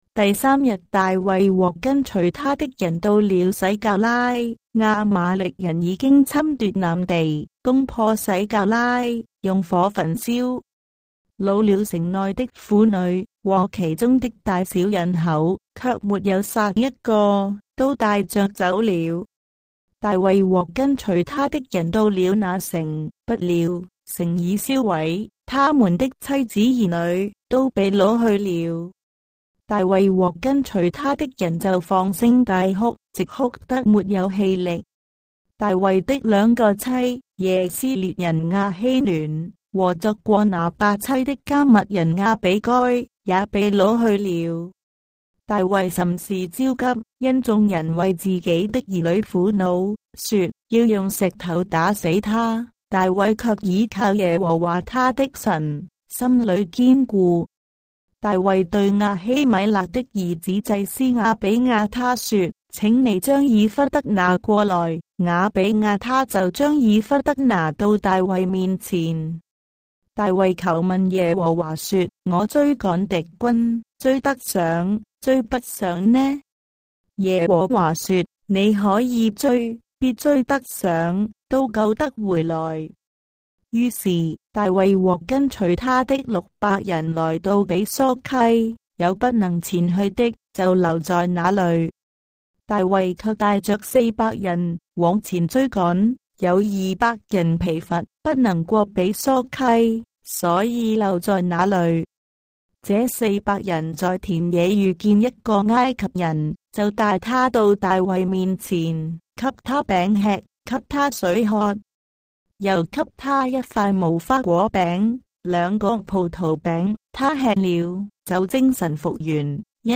章的聖經在中國的語言，音頻旁白- 1 Samuel, chapter 30 of the Holy Bible in Traditional Chinese